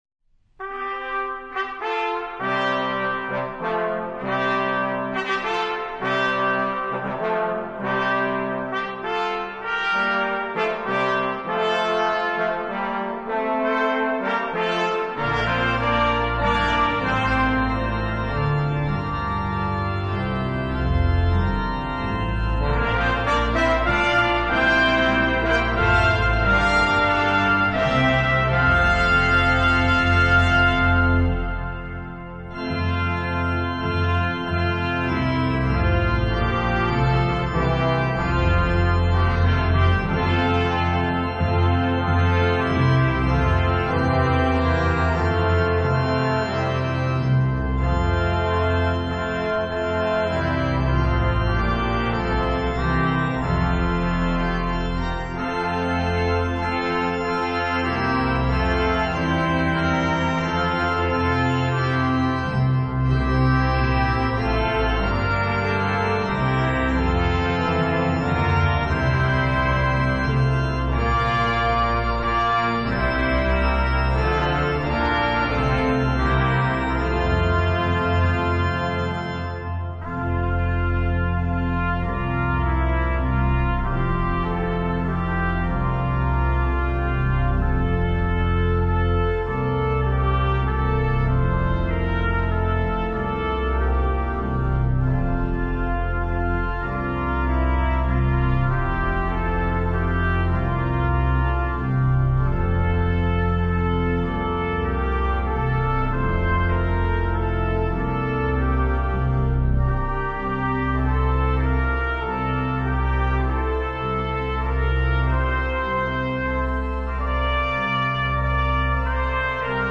Voicing: Congregation, Optional Soprano Descant